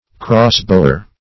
crossbower.mp3